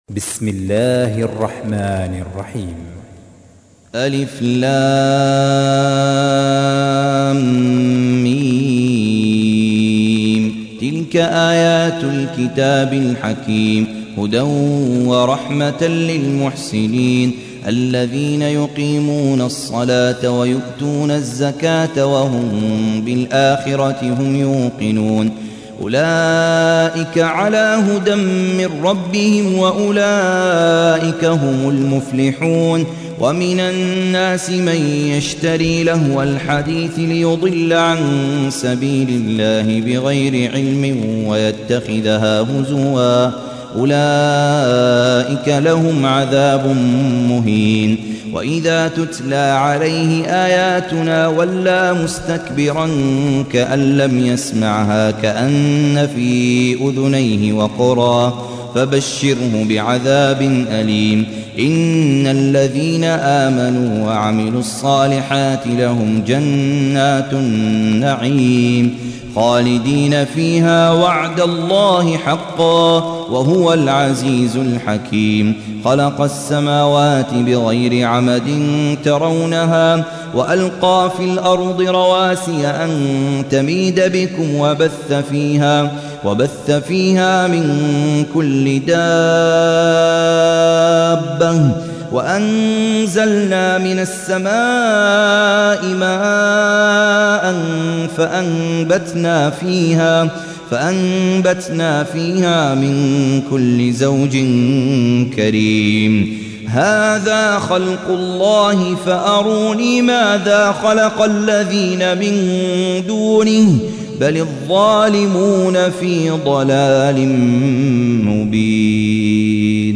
تحميل : 31. سورة لقمان / القارئ خالد عبد الكافي / القرآن الكريم / موقع يا حسين